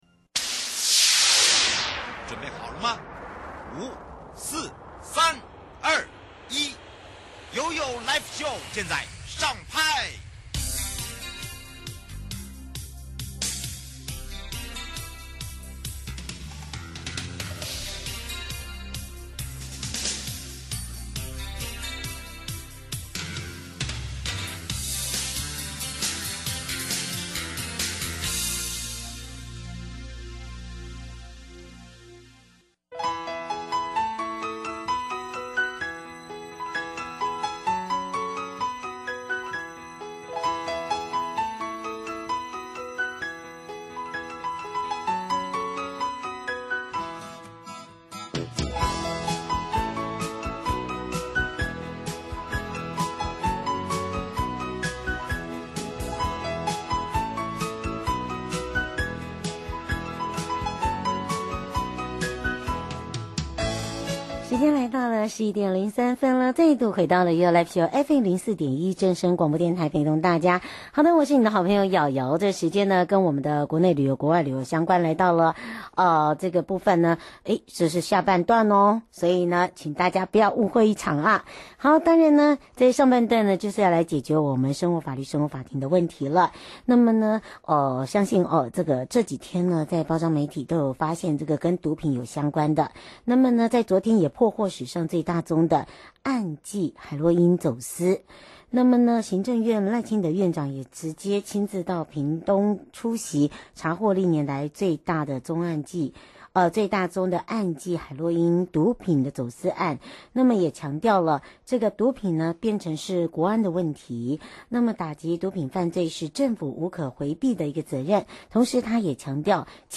內政部 徐國勇部長 節目內容： 1.